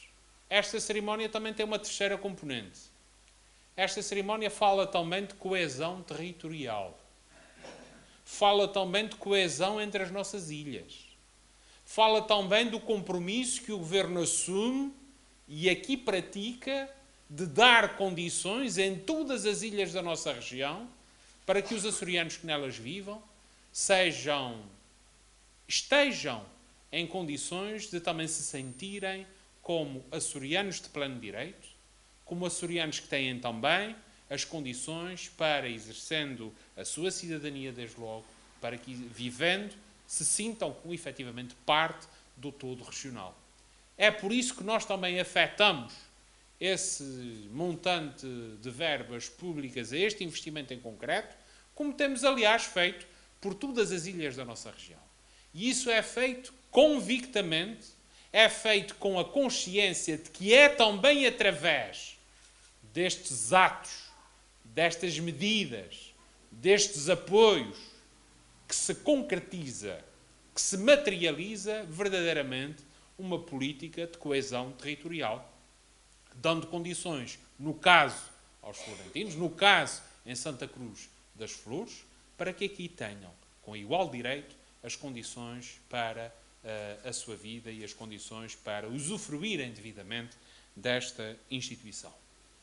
Vasco Cordeiro falava na apresentação do projeto de arquitetura da requalificação e ampliação do Lar de Idosos da Santa Casa da Misericórdia de Santa Cruz das Flores, um investimento de cerca de 1,7 milhões de euros a realizar através de um contrato de cooperação com a instituição.